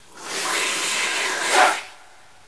Effetto rumore
Rumore bianco modulato con voce riverberata finale.